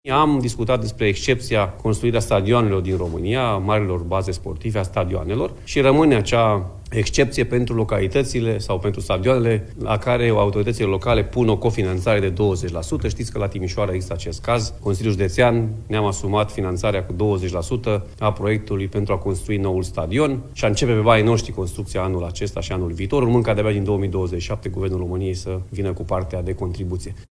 Președintele Uniunii Consiliilor Județene, Alfred Simonis: Rămâne o excepție pentru stadioanele la care autoritățile locale pun o cofinanțare de 20%